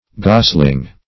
Gosling \Gos"ling\, n. [AS. g[=o]s goose + -ling.]